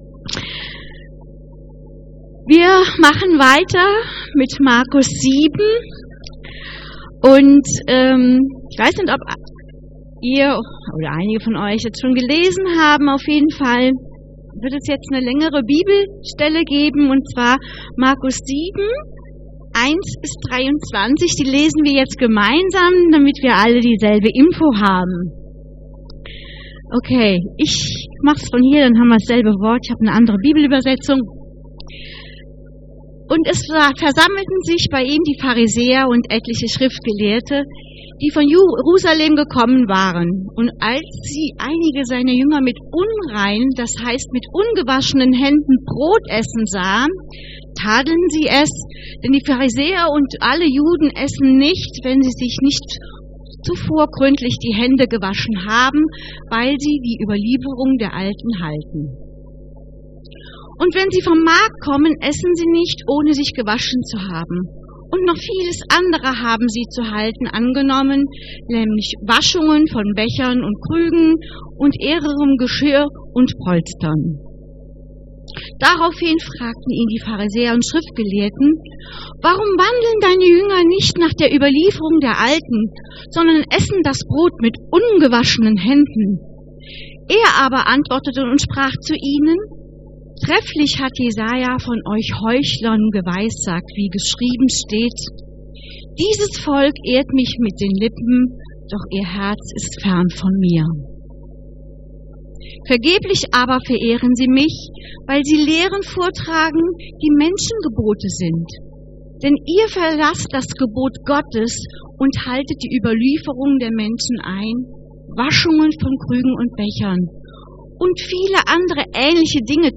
Predigt 30.05.2021